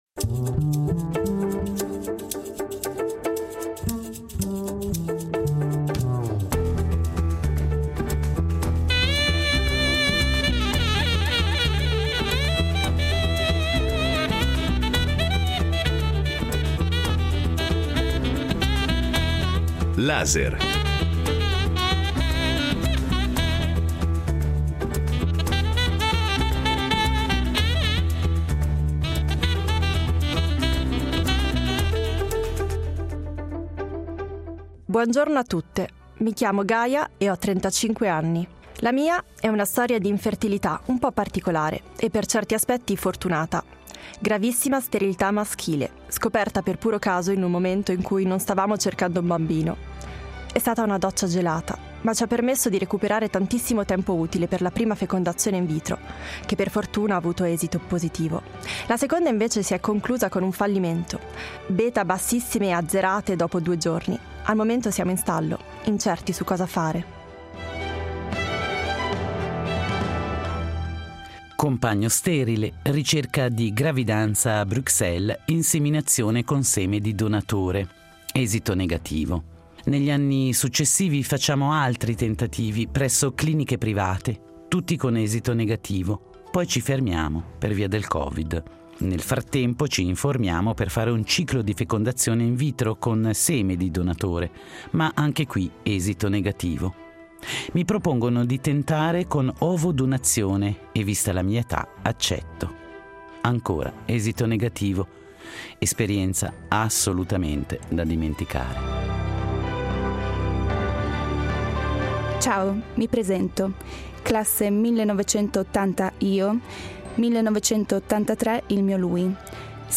L’infertilità diffusa discussa con le testimonianze di uno psicanalista e di una bioeticista